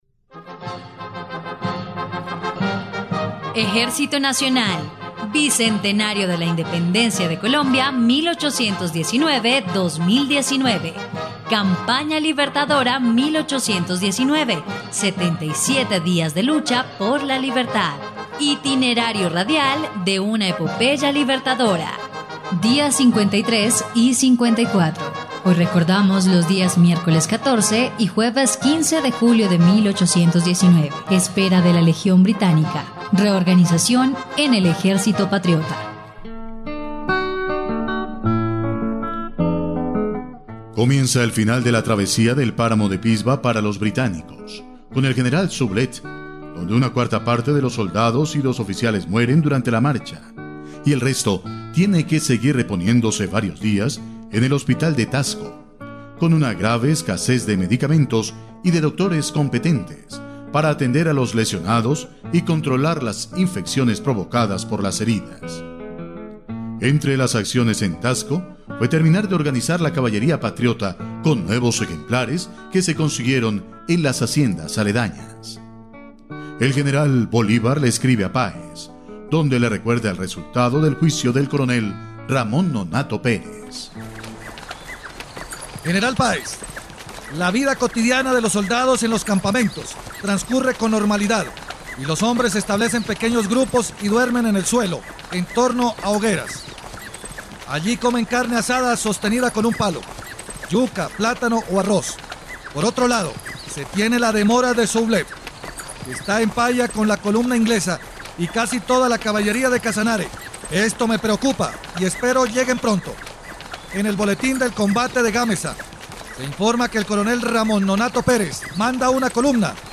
dia_53_y_54_radionovela_campana_libertadora.mp3